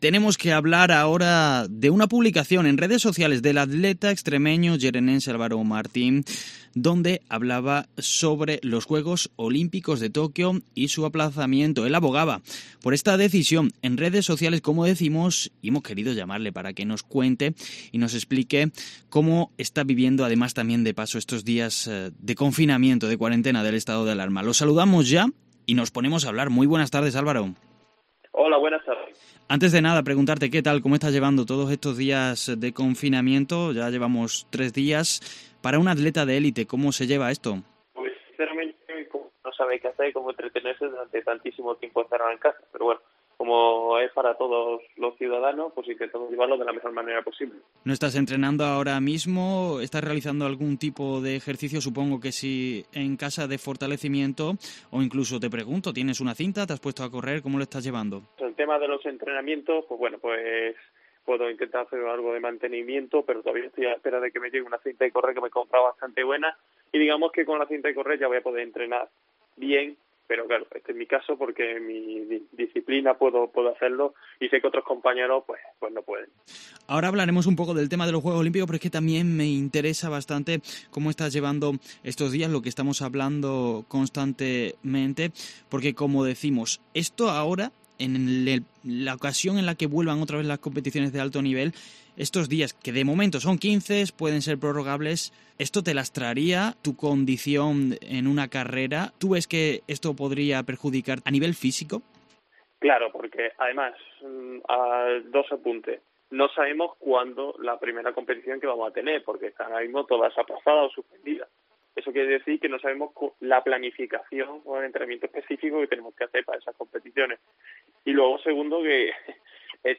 ENTREVISTA A ALVARO MARTÍN